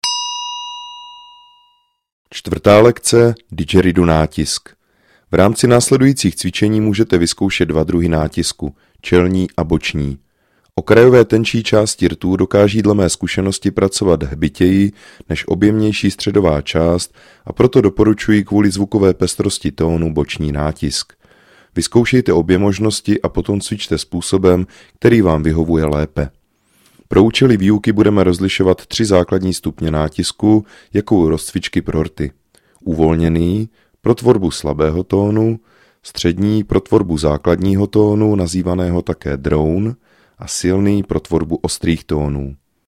VÝUKA HRY NA DIDGERIDOO I.
Didgeridoo je nástroj, jehož hluboký tón dokáže ukotvit pozornost v přítomném okamžiku.
Track 12 - 4 lekce - Didgeridoo natisk.mp3